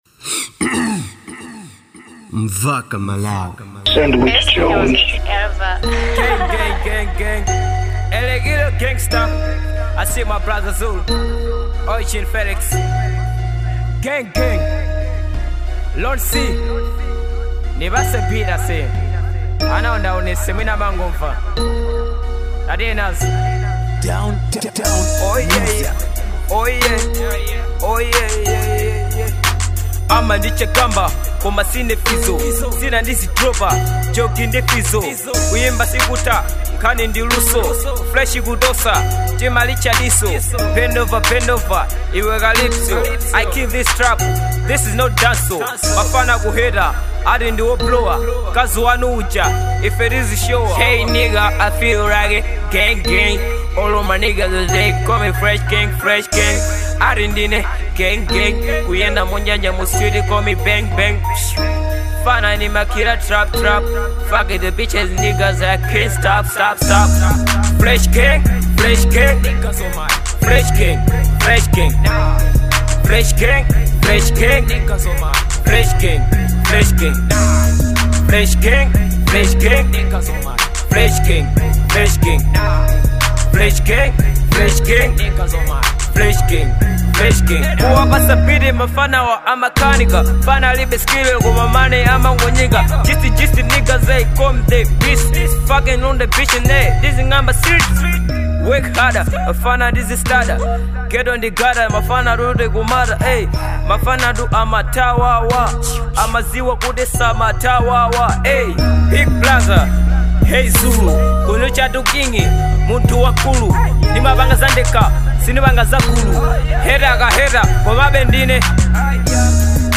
type: trap